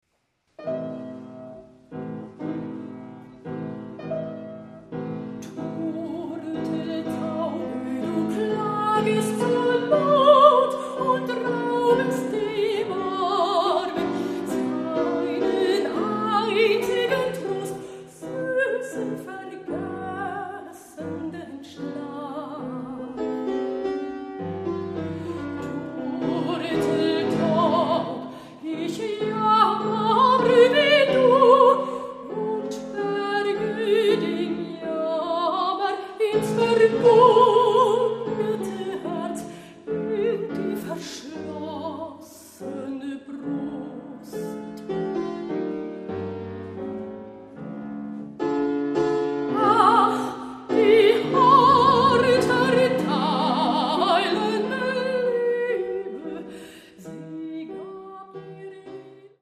Mezzosopran
Hammerflügel